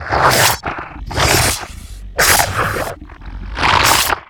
horror
Werewolf Attack Growl